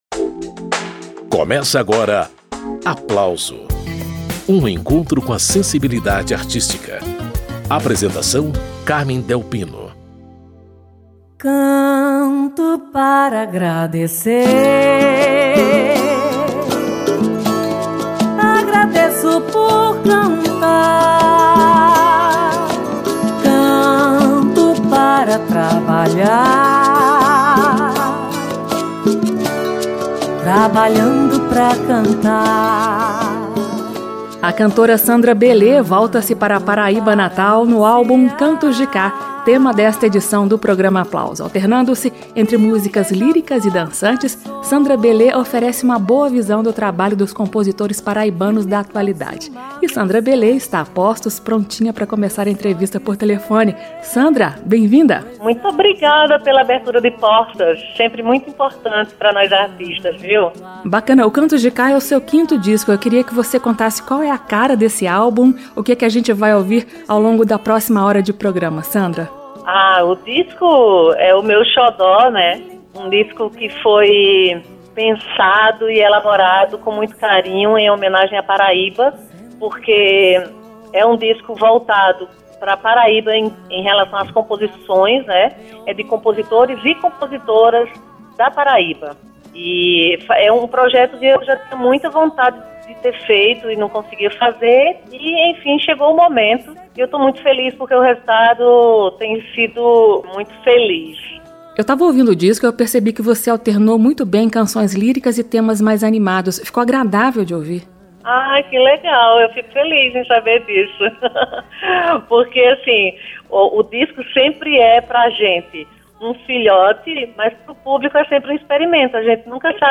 um álbum com arranjos modernos